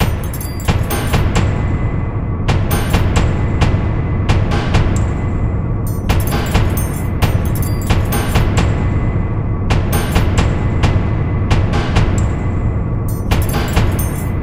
描述：在箱子被移动时，玻璃与玻璃之间的快速叮当声（中等音调）。 叮当声。
Tag: 玻璃盒中 玻璃瓶 钛纳克